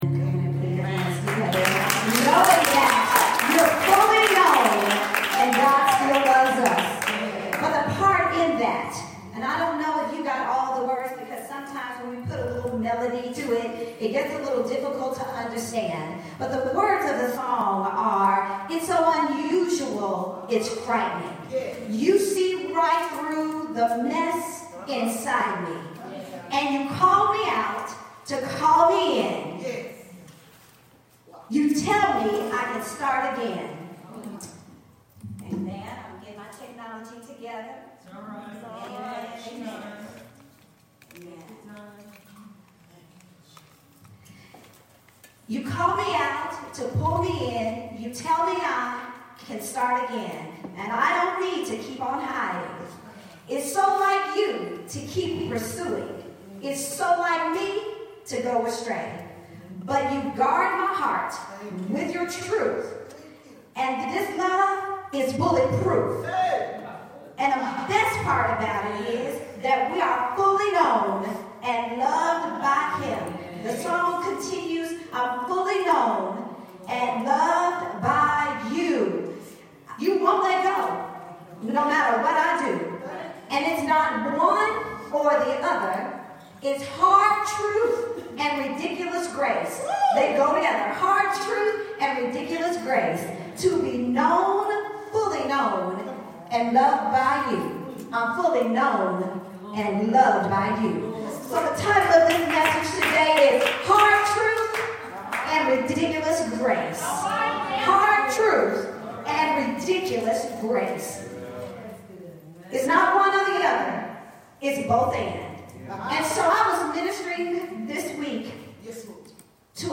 Sermons | Bountiful Blessings World Fellowship